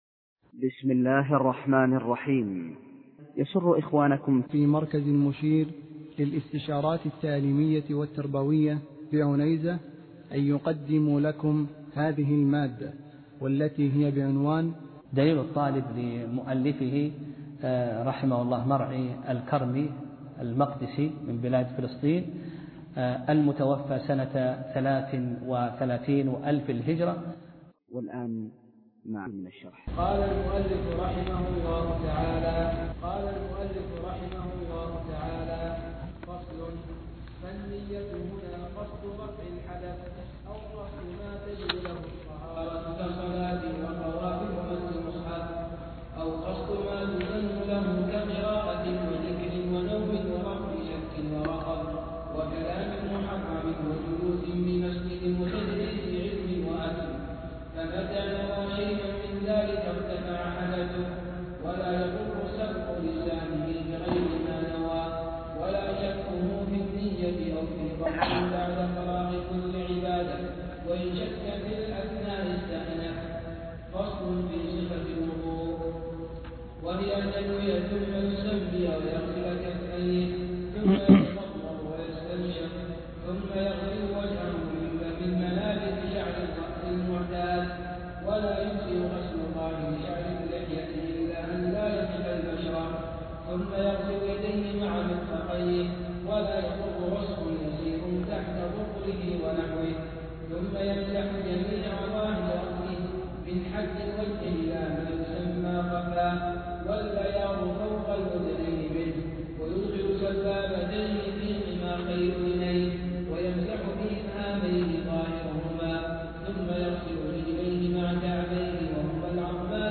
درس (8) : باب الوضوء: [النية / صفة الوضوء / سنن الوضوء]